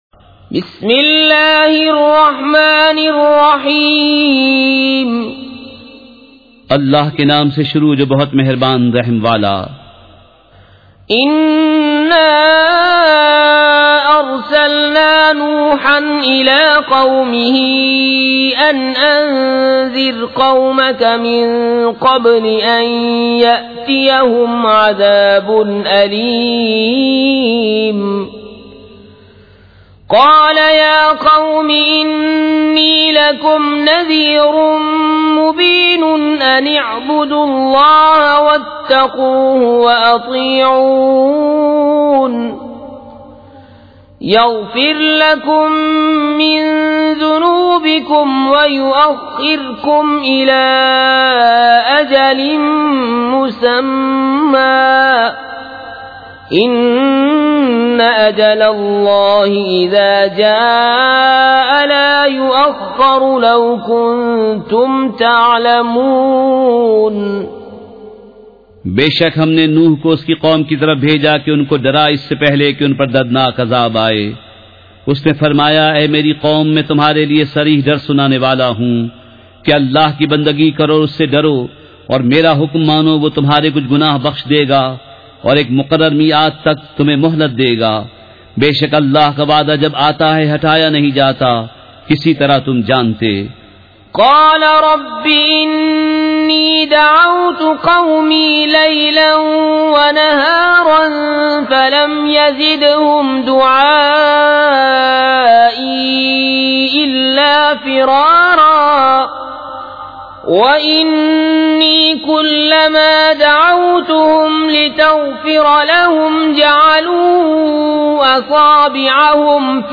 سورۃ النوح مع ترجمہ کنزالایمان ZiaeTaiba Audio میڈیا کی معلومات نام سورۃ النوح مع ترجمہ کنزالایمان موضوع تلاوت آواز دیگر زبان عربی کل نتائج 2883 قسم آڈیو ڈاؤن لوڈ MP 3 ڈاؤن لوڈ MP 4 متعلقہ تجویزوآراء